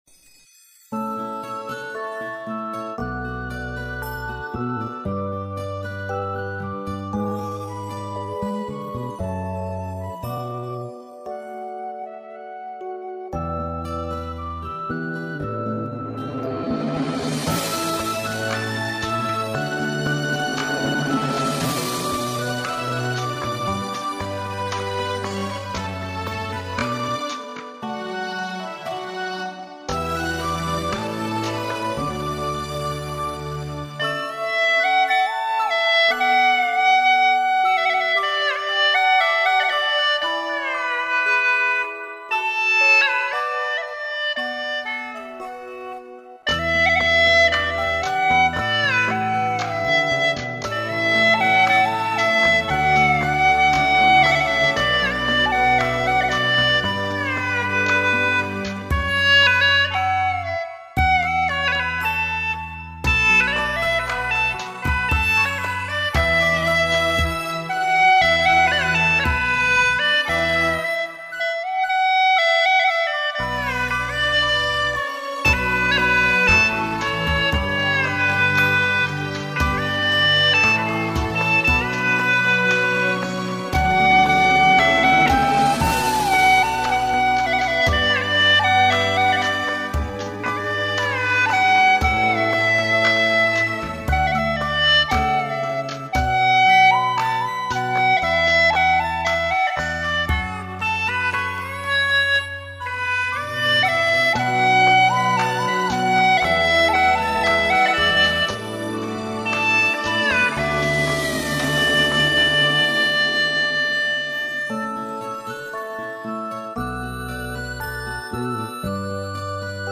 调式 : D 曲类 : 古风